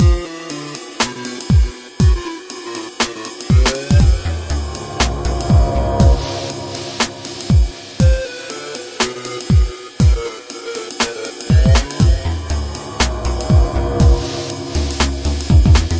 描述：我在MetaSynth做过的另一个循环。 120 bpm。
Tag: 回路 电子 MetaSynth 120-BPM 合成器 节奏 音乐 节奏